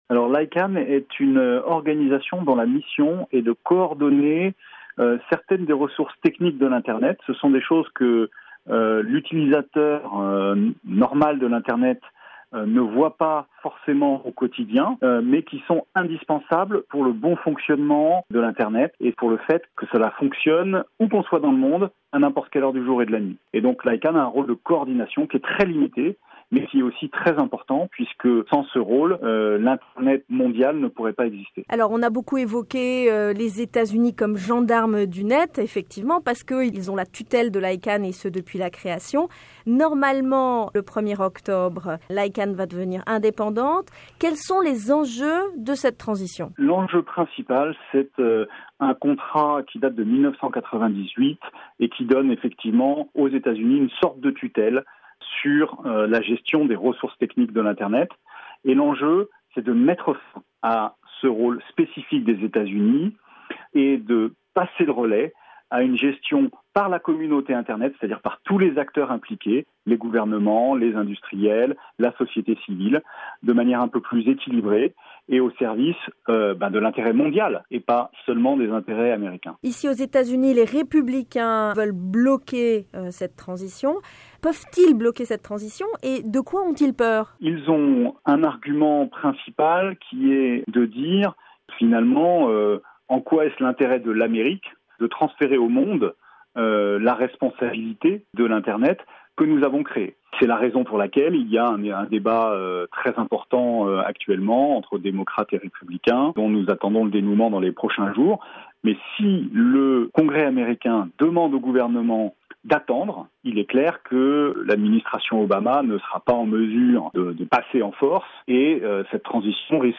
Icann / tutelle américaine du net - interview